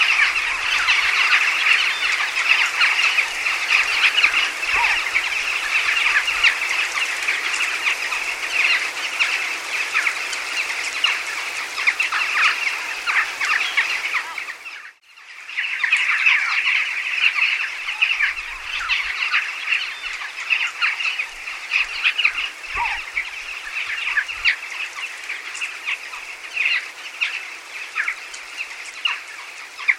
Choucas des tours - Mes zoazos
choucas-des-tours.mp3